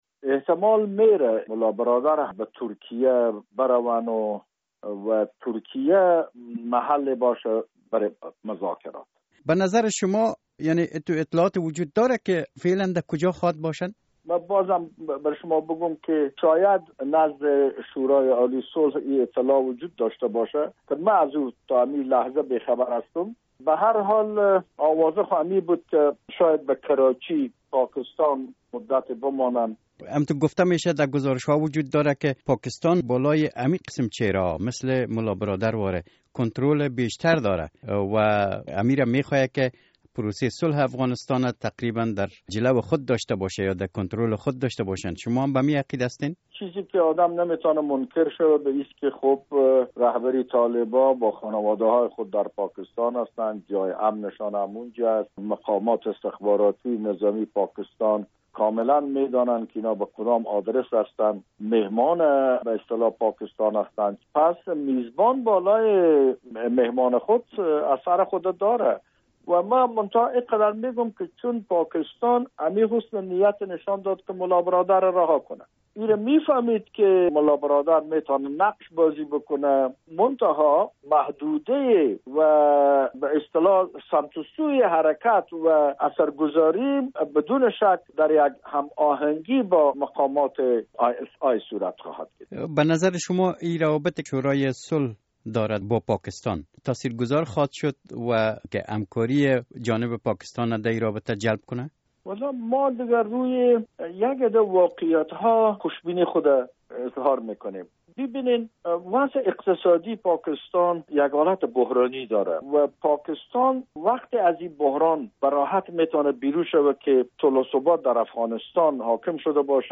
مصاحبه: ملابرادر هنوز هم بین طالبان به حیث شخص دوم جایگاه دارد